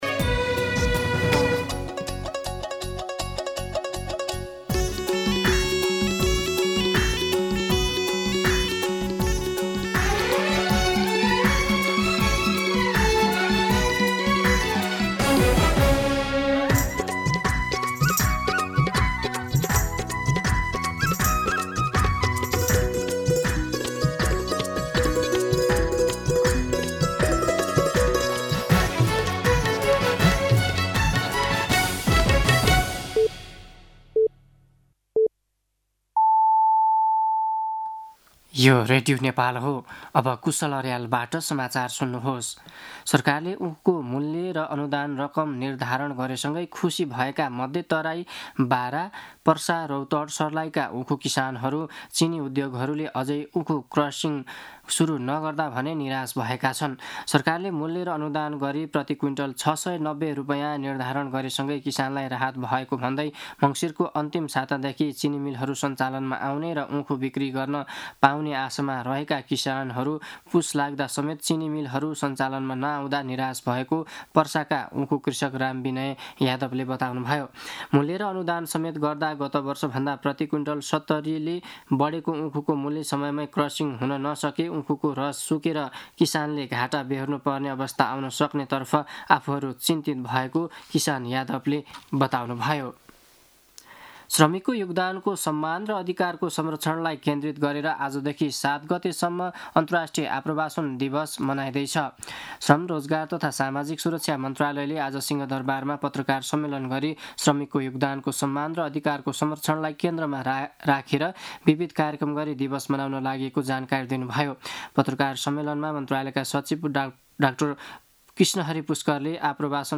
दिउँसो ४ बजेको नेपाली समाचार : १ पुष , २०८२